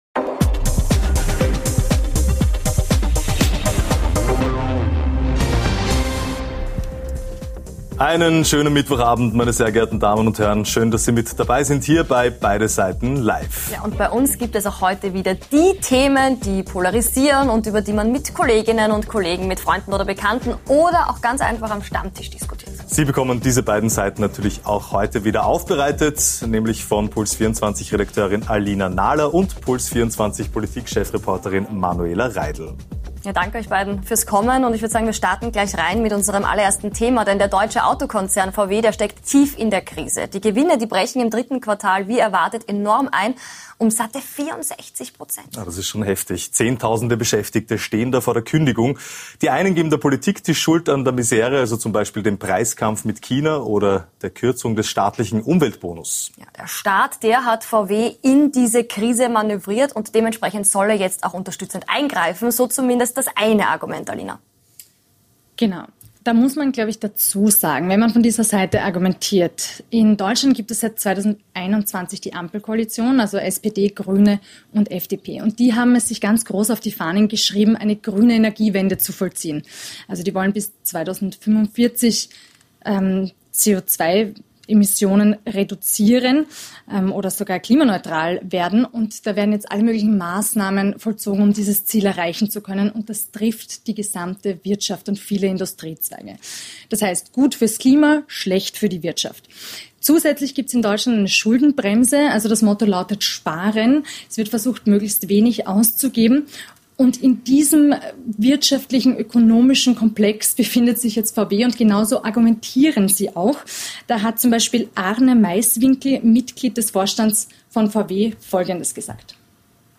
Und nachgefragt haben wir heute beim ehemaligen freiheitlichen Nationalratspräsidenten und FPÖ-Spitzenkandidaten für die Burgenlandwahl im Jänner Norbert Hofer - Im Interview des Tages.